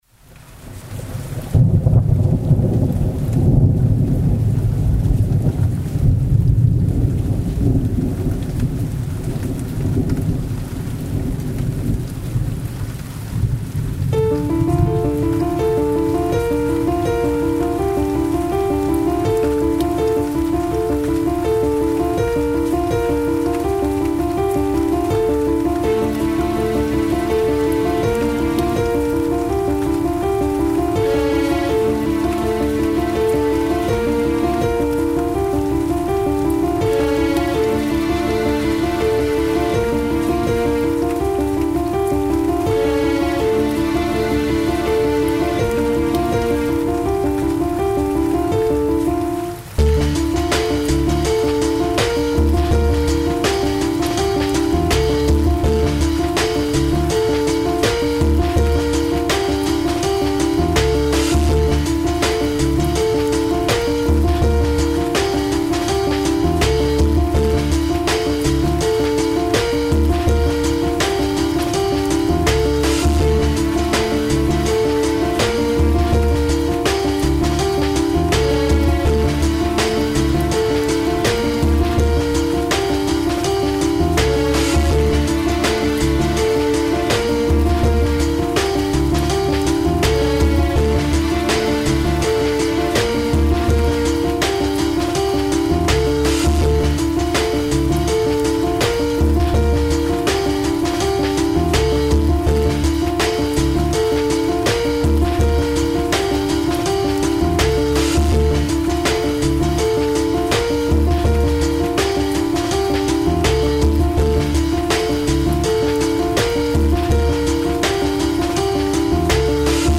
Dirt & Grime instrumental